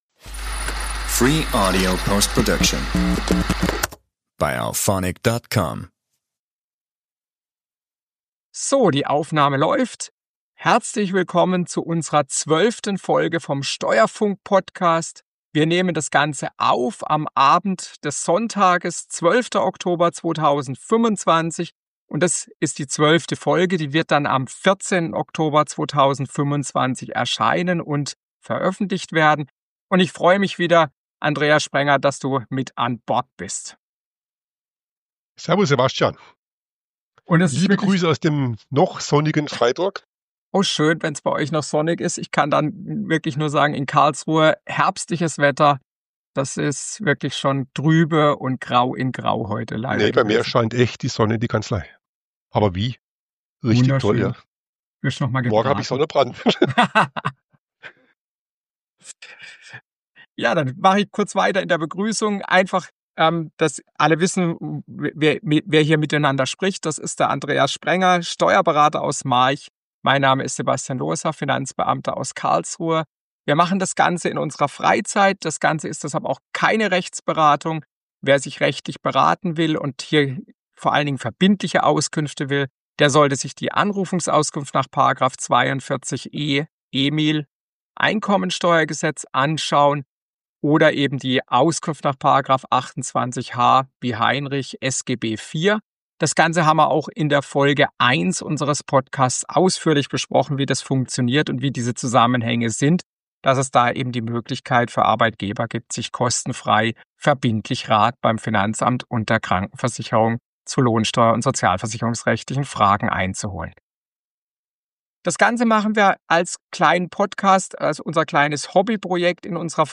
Dieses Mal haben wir an der Tonqualität gearbeitet - wir hoffen, das gefällt!